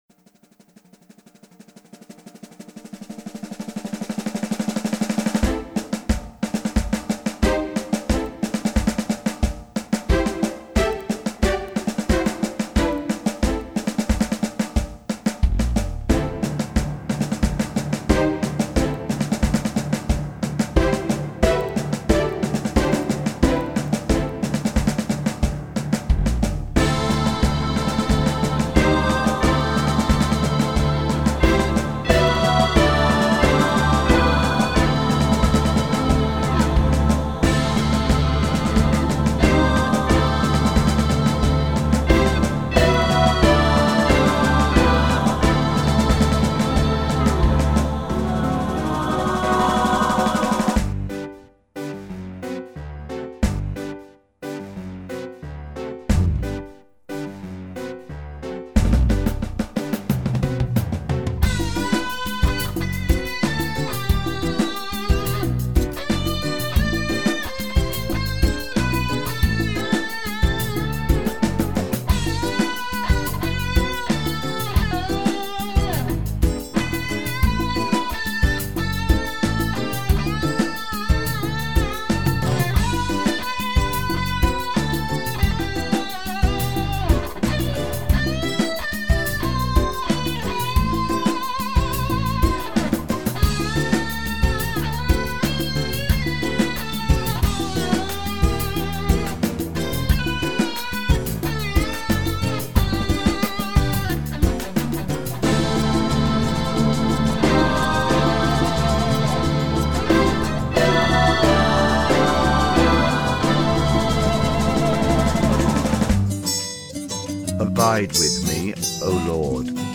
Christian hymn